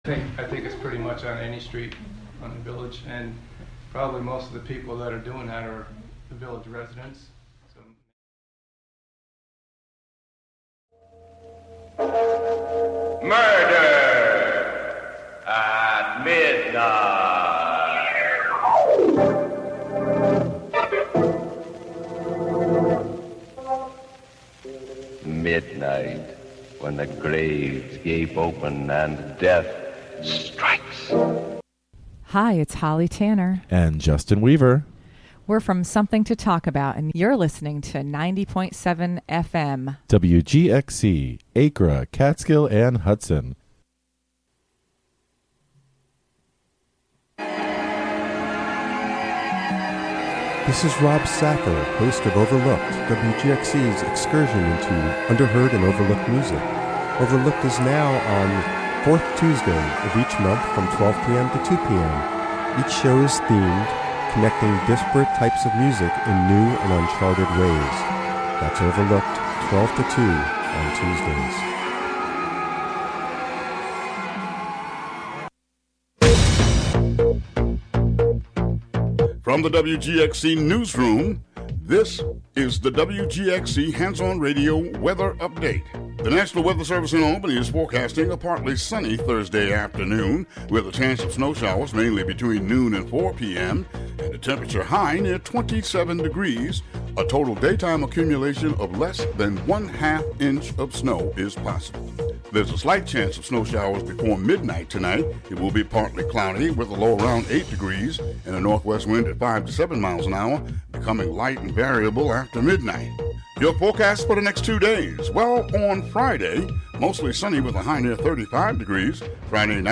You’re invited to put on your boots and join us every Friday morning as we meander through the wild areas of our modern urban landscape, exploring contemporary and classic Americana, folk, country and elusive material that defies genre.
Broadcast live from the Hudson studio.